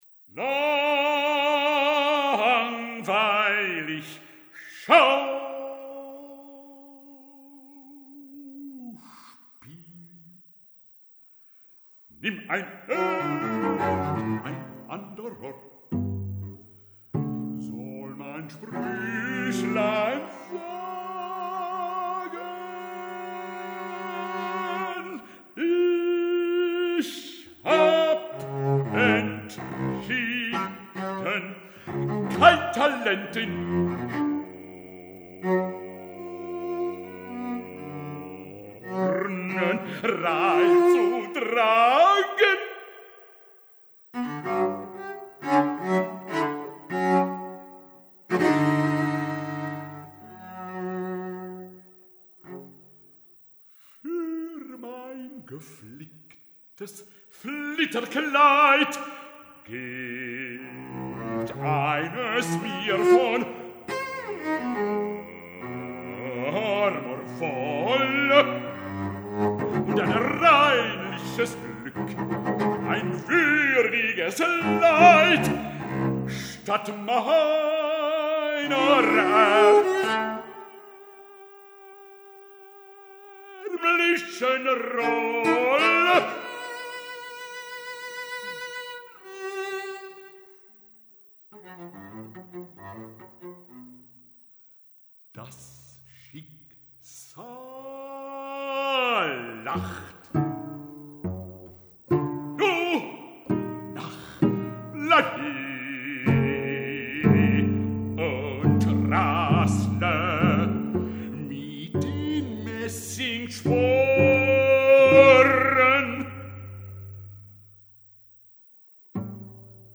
• Bezetting: bas-bariton, violoncello.
van een concert op kasteel Ehrenstein te Kerkrade